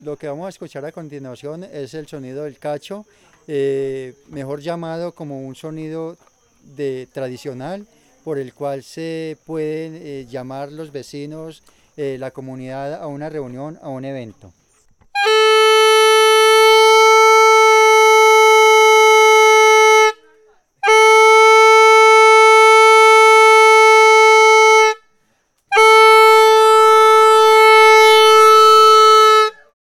Sonido del cuerno en Fenicia.mp3 (700.41 KB)
El sonido del cuerno de Fenicia.